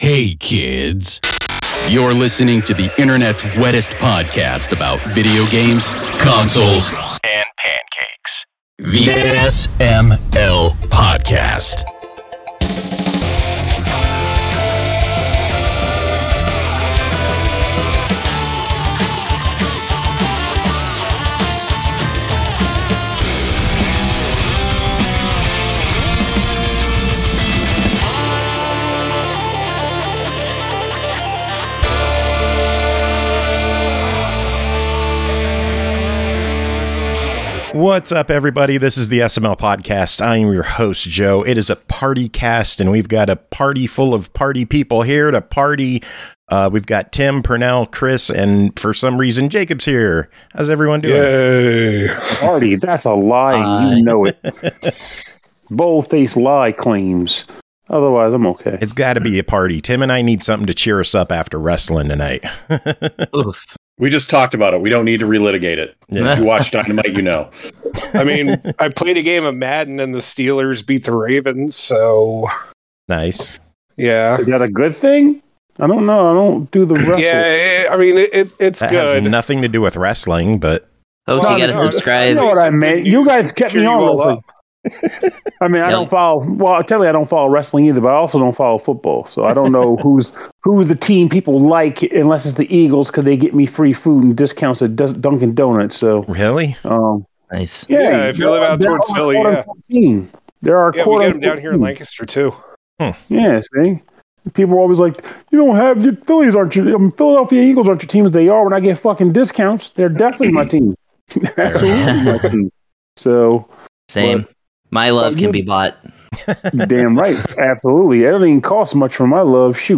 It’s a PartyCast with a nice batch of reviews including a ton of tag teams, so let’s get going!